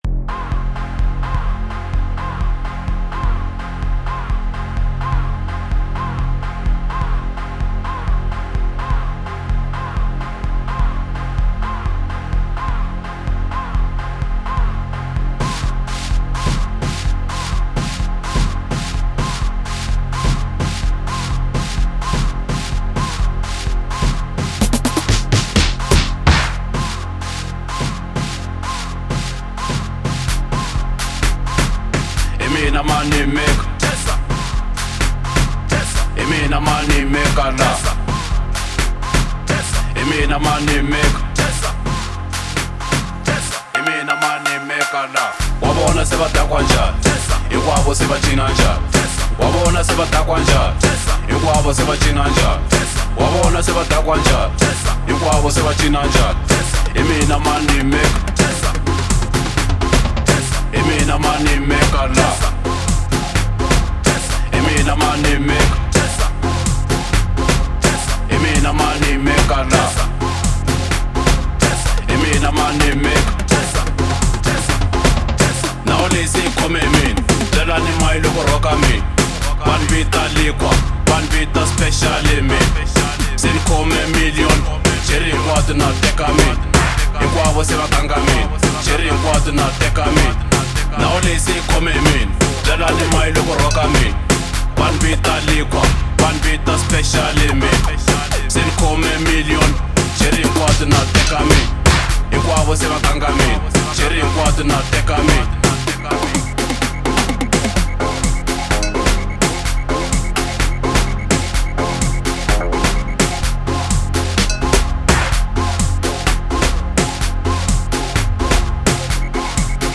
04:56 Genre : House Size